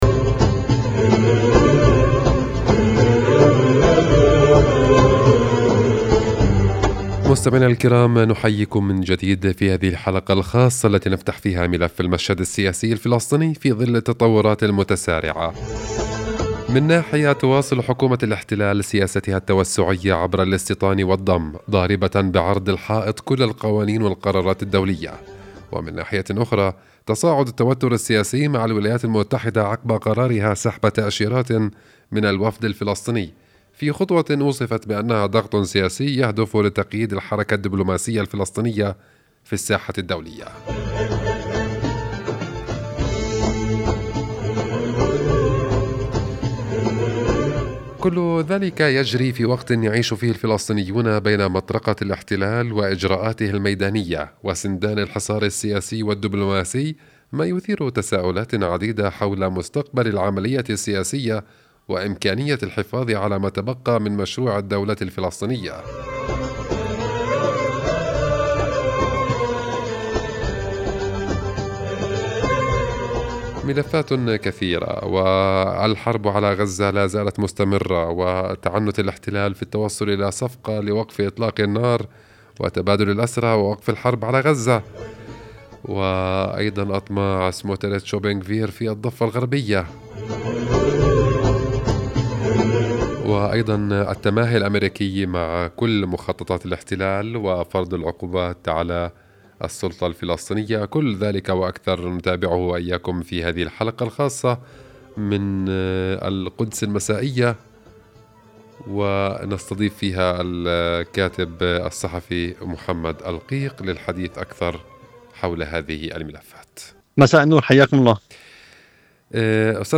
فتحت إذاعة قناة القدس ملف الوضع السياسي الفلسطيني في ظل التطورات المتلاحقة، من سحب واشنطن لتأشيرات وفد فلسطيني، إلى تصاعد سياسات الاستيطان والضم التي تنفذها حكومة الاحتلال الإسرائيلي.